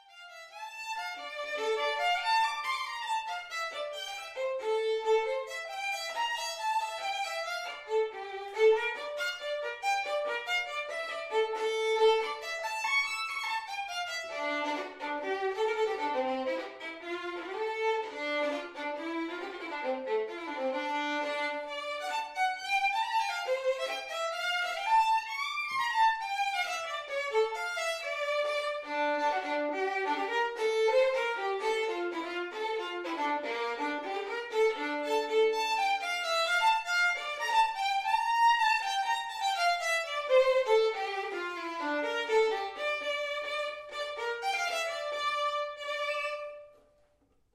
Diese führe sie solo oder mit Band-Besetzung auf.
solo